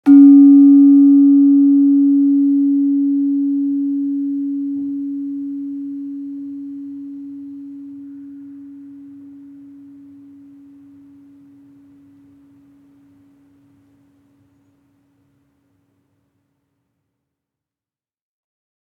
Gender-1-C#3-f.wav